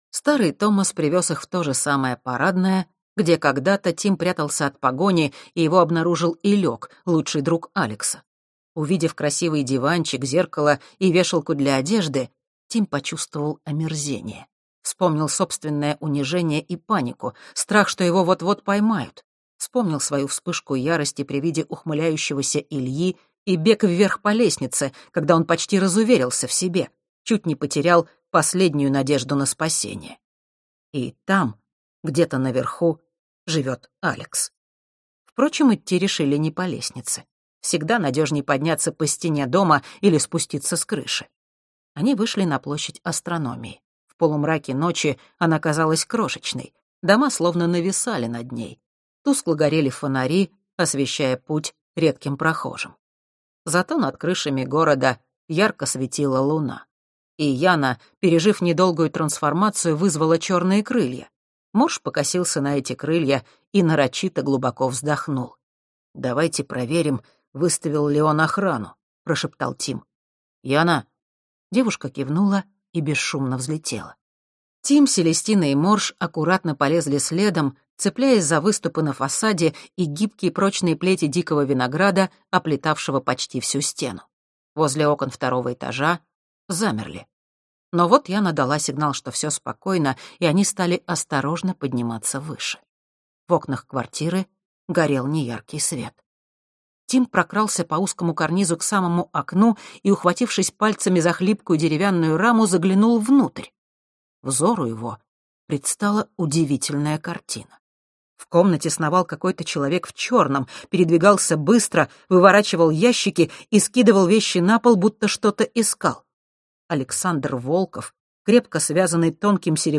Аудиокнига Лунастры. Танец белых карликов | Библиотека аудиокниг